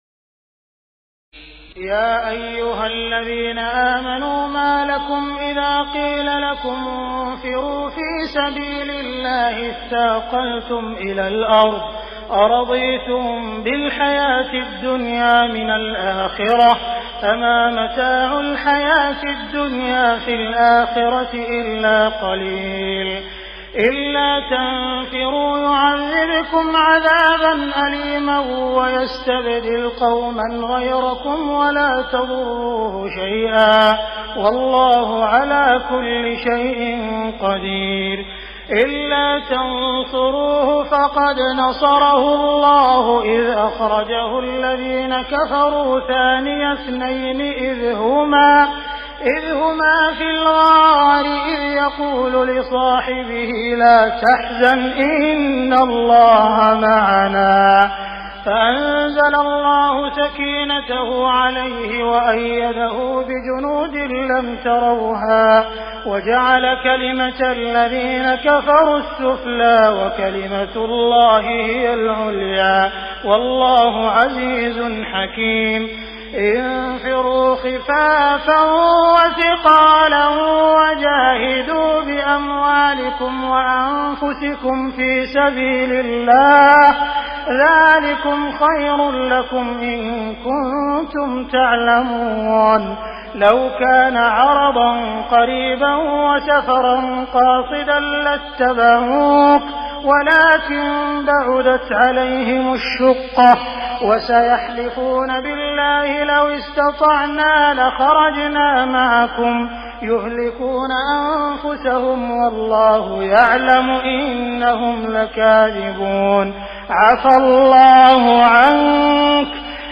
تراويح الليلة العاشرة رمضان 1418هـ من سورة التوبة (38-110) Taraweeh 10 st night Ramadan 1418H from Surah At-Tawba > تراويح الحرم المكي عام 1418 🕋 > التراويح - تلاوات الحرمين